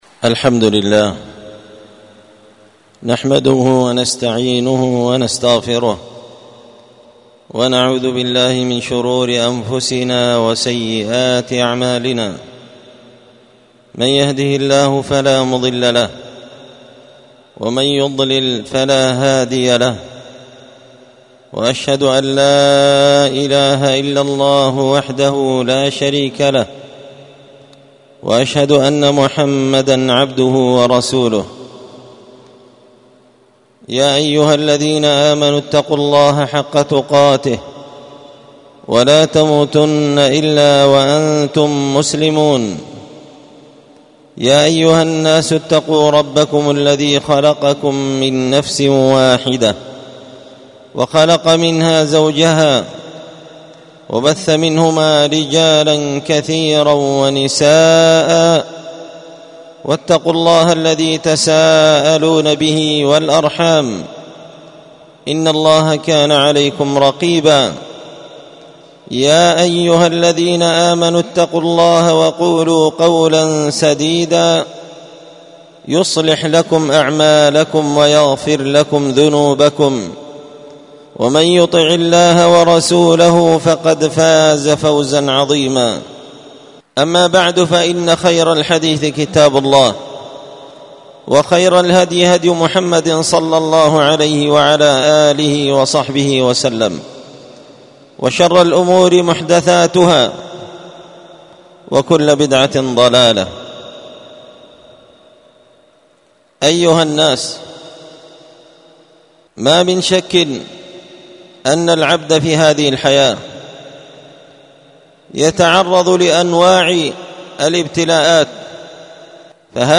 خطبة جمعة
ألقيت هذه الخطبة بدار الحديث السلفية بمسجد الفرقان قشن -المهرة-اليمن